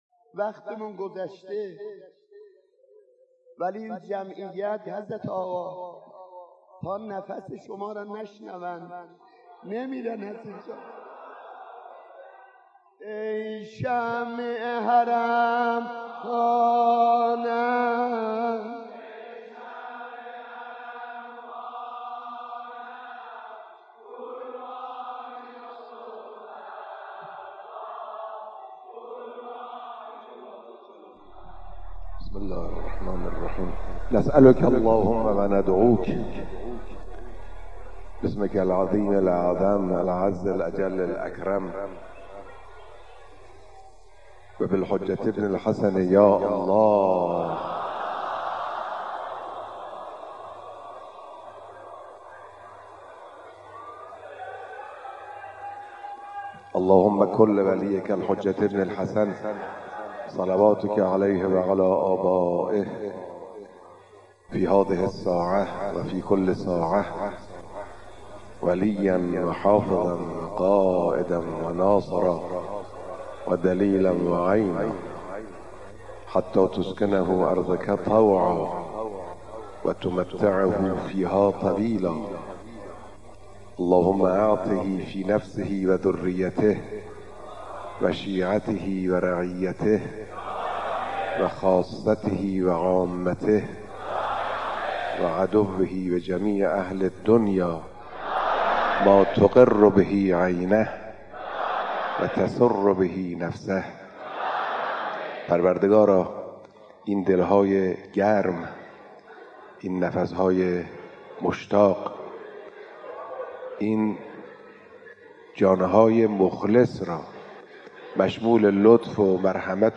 دعای رهبر انقلاب در پایان مراسم عزاداری اربعین
قرائت دعا